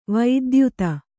♪ vaidyuta